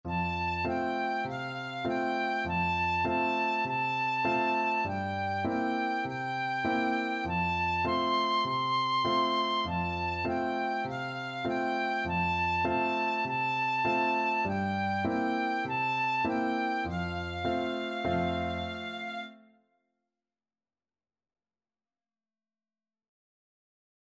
Tin Whistle
Traditional Music of unknown author.
F major (Sounding Pitch) (View more F major Music for Tin Whistle )
4/4 (View more 4/4 Music)
F6-C7